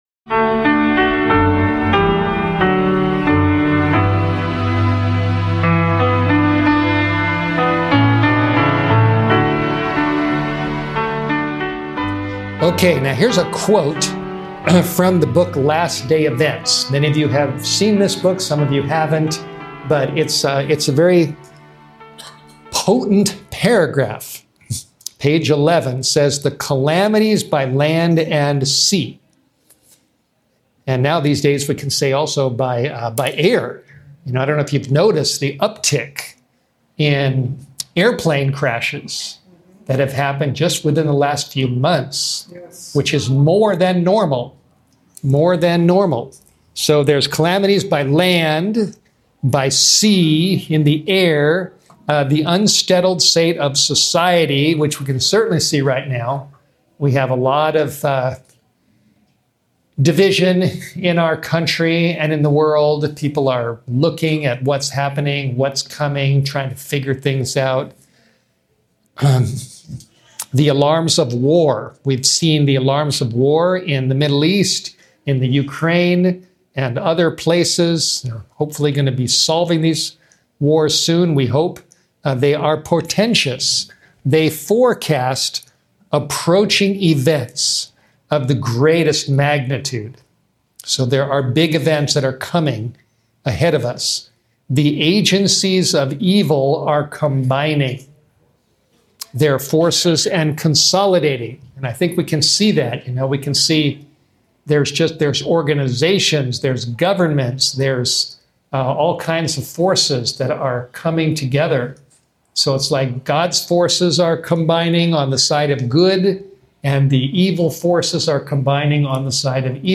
This eye-opening sermon explores biblical prophecy, spiritual preparedness, financial stewardship, and the true meaning of the Mark of the Beast. Discover how faith, holistic living, and unity in Christ can help believers navigate the challenges ahead!